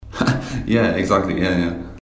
Tags: interview